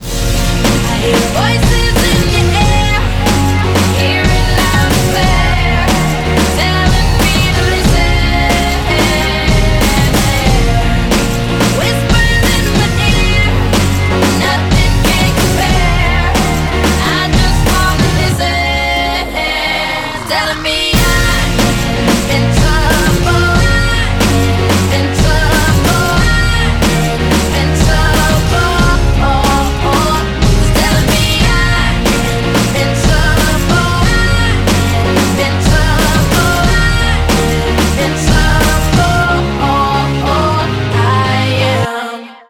Драйвовые
Rap-rock